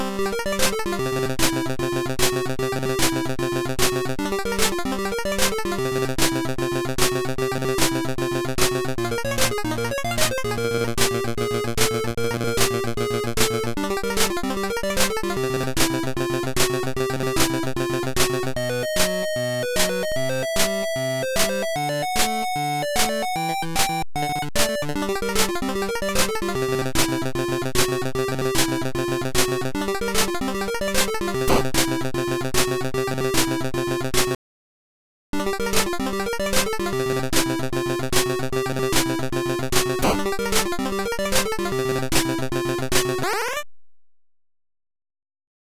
All to the single, short,
catchy tune this game has! (IT ROCKS!)